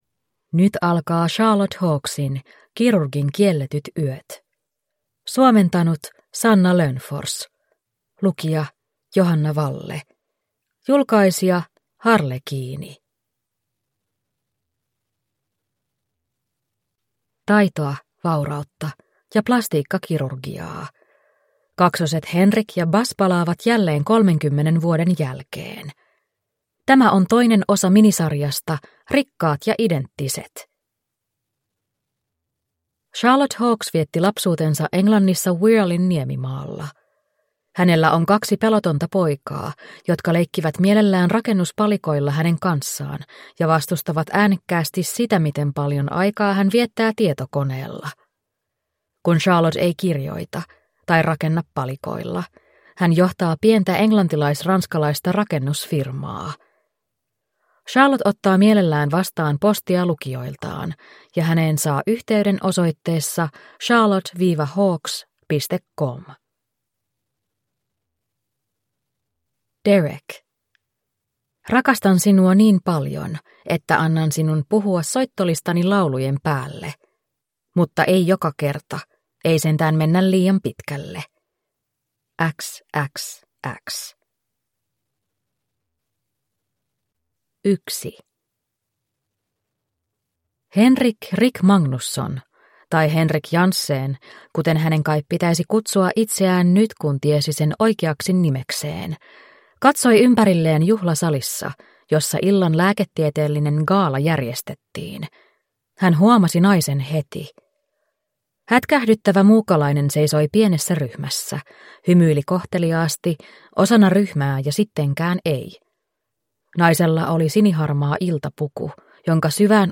Kirurgin kielletyt yöt (ljudbok) av Charlotte Hawkes